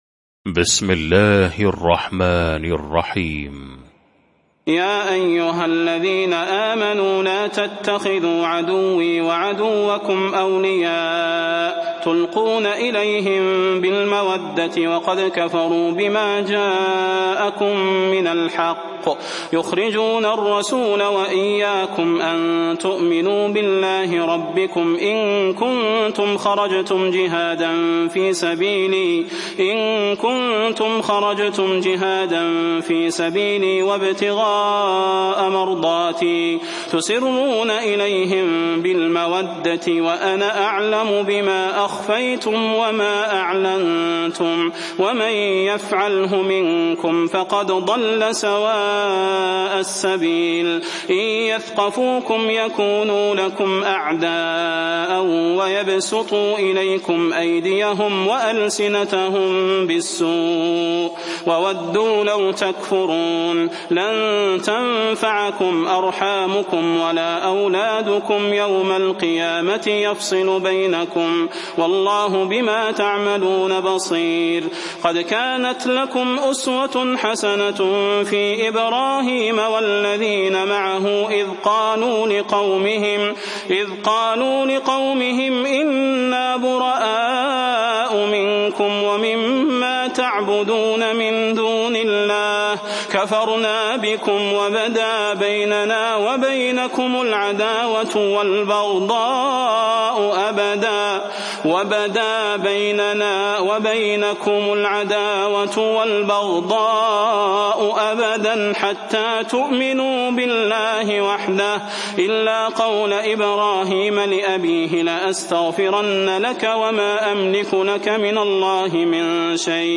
المكان: المسجد النبوي الشيخ: فضيلة الشيخ د. صلاح بن محمد البدير فضيلة الشيخ د. صلاح بن محمد البدير الممتحنة